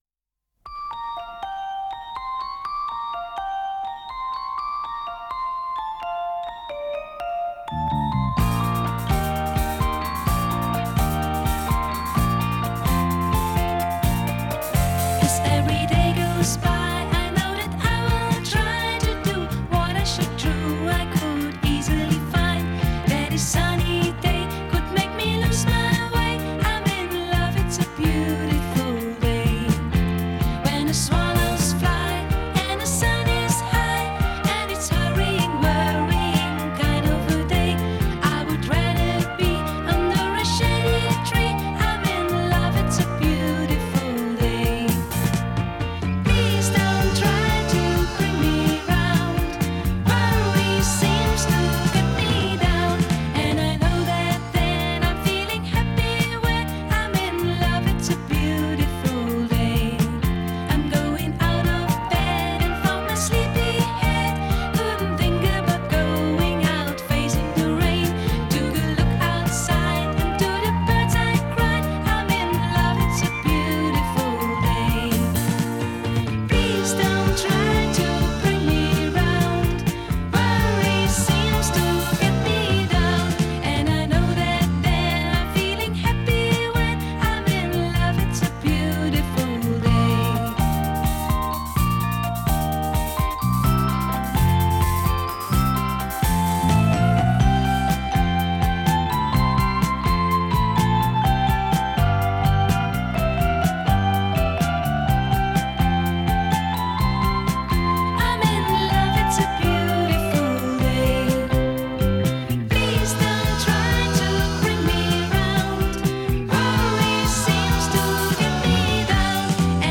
Жанр: Electronic, Rock, Funk / Soul, Pop
Recorded At – Soundpush Studios